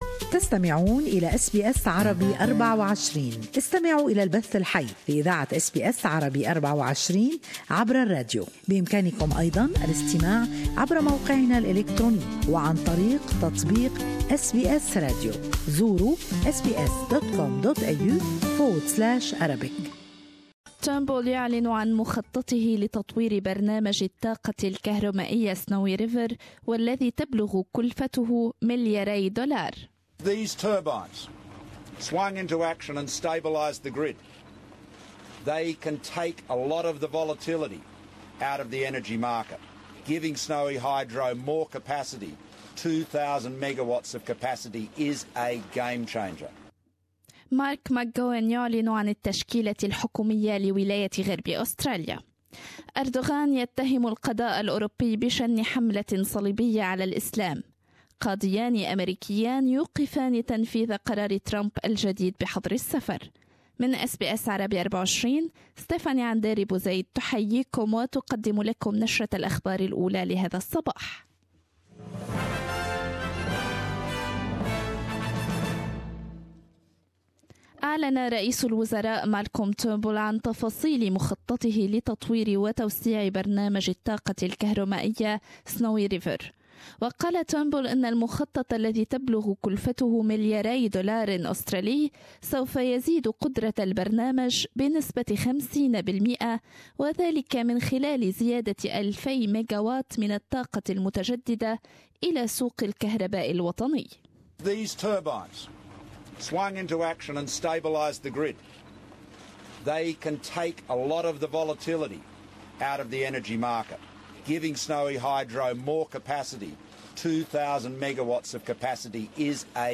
News Bulletin